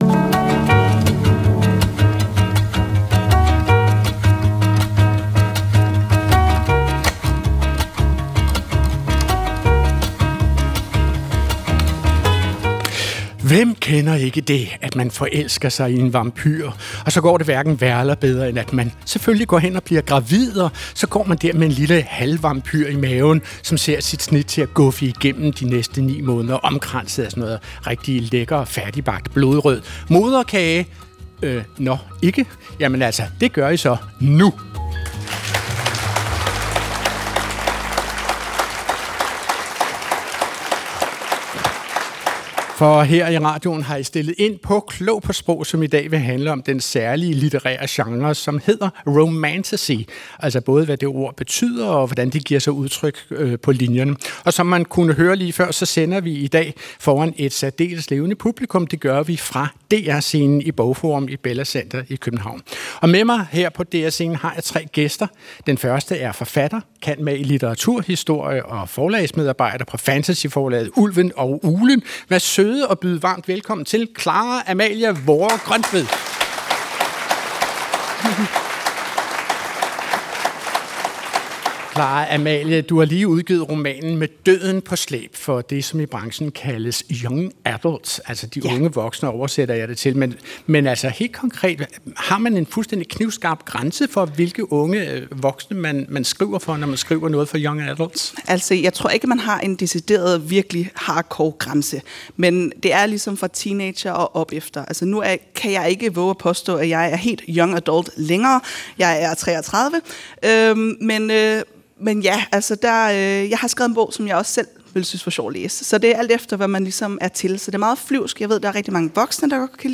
Klog på Sprog finder svarene på DR-scenen foran et levende publik…